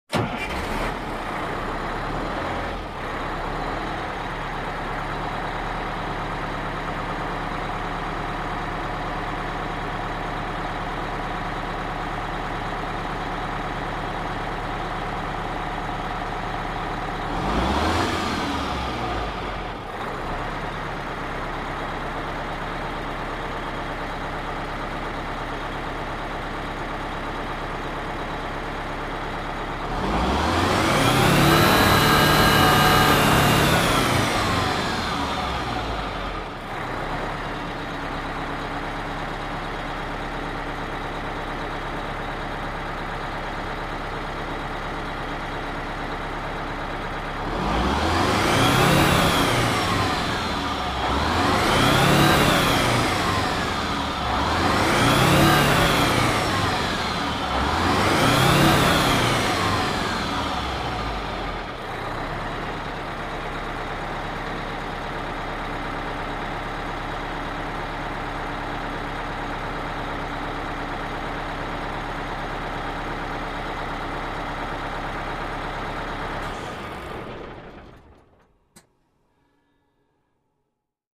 Автобус заводится с перегазовкой и глушится